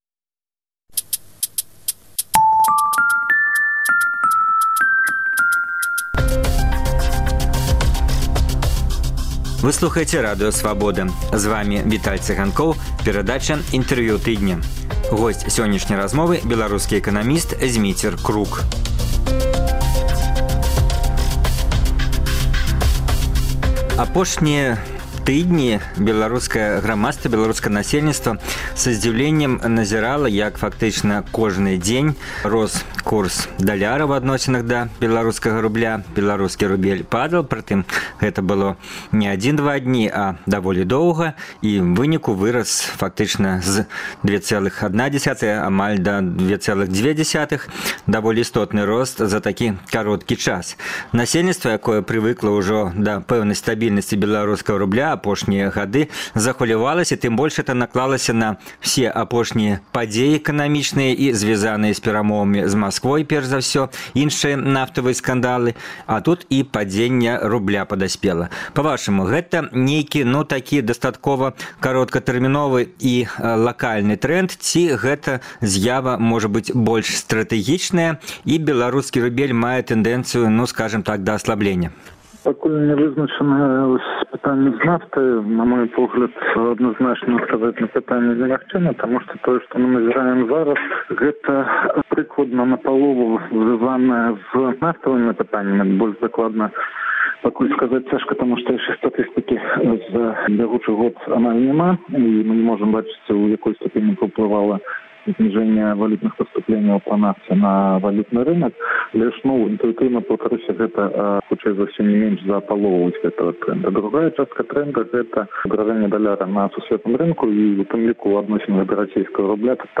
У праграме: Інтэрвію тыдня.